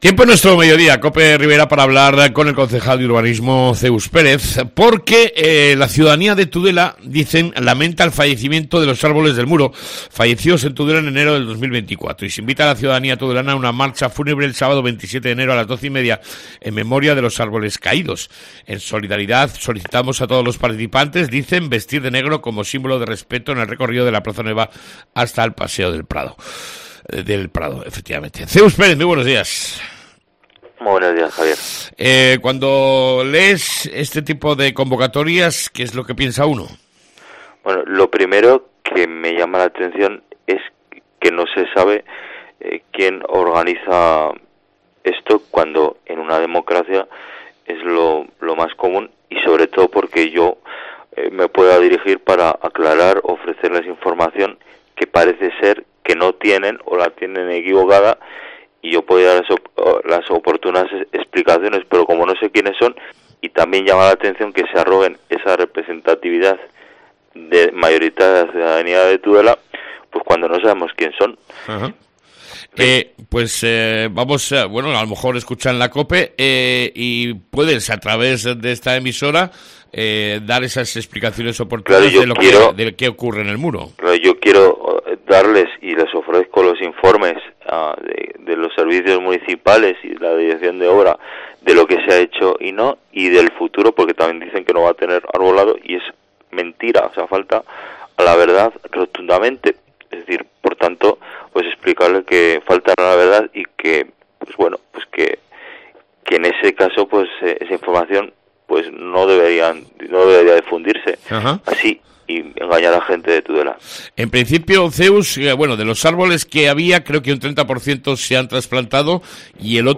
ENTREVISTA CON EL CONCEJAL DE URBANISMO, ZEUS PÉREZ